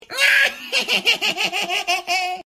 Devious Laugh Sound Effect Free Download
Devious Laugh